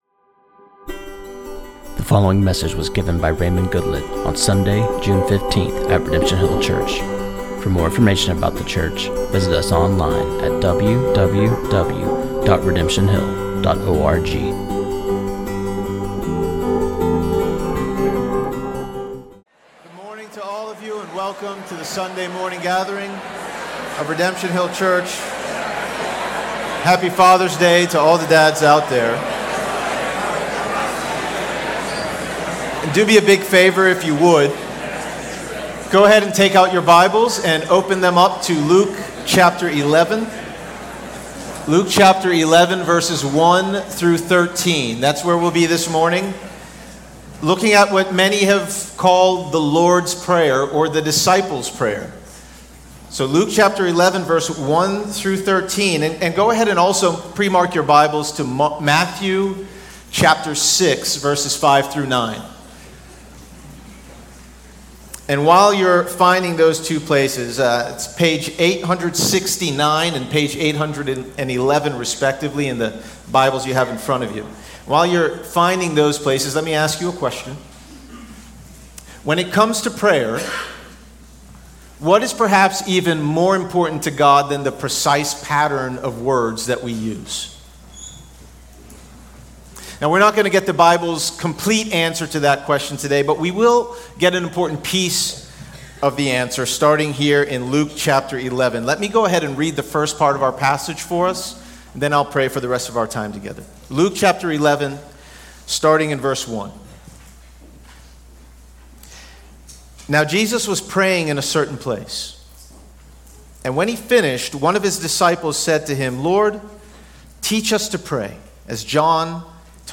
This sermon on Luke 11:1-13